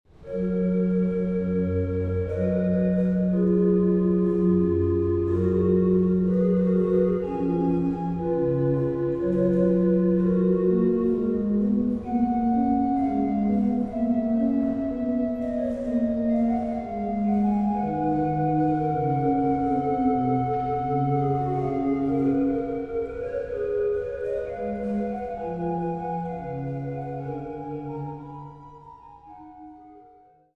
an den Freiberger Silbermann-Orgeln